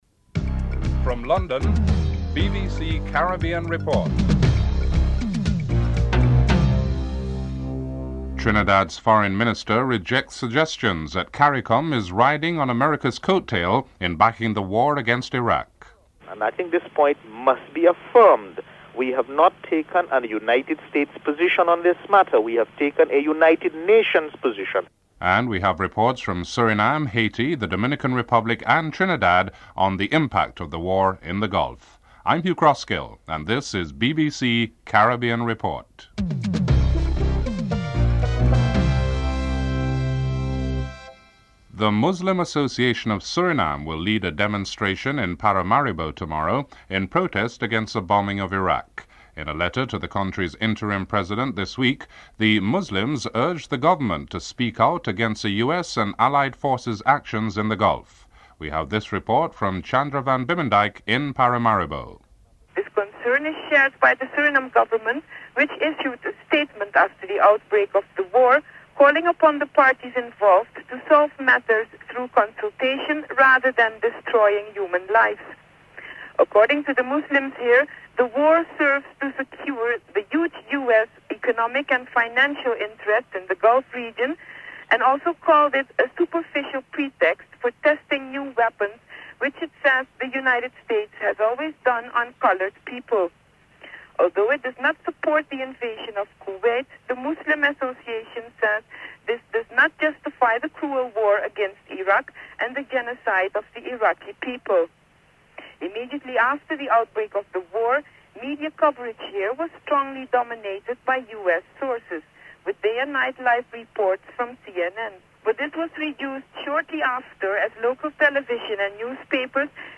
1. Headlines (00:00- 00:42)